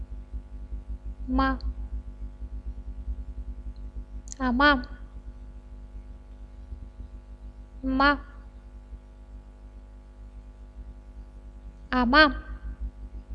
Voiced Sounds of the Romanian Language
Consonants - Speaker #3